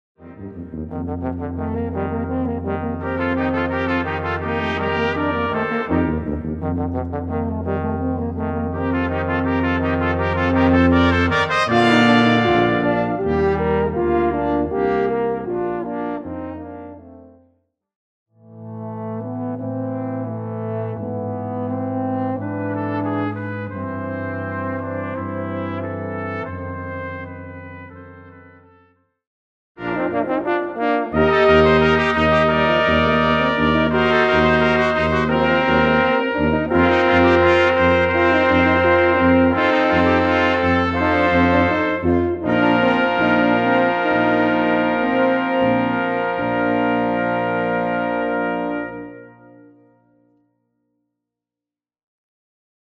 Gattung: Weihnachtsmusik für Blechbläserquintett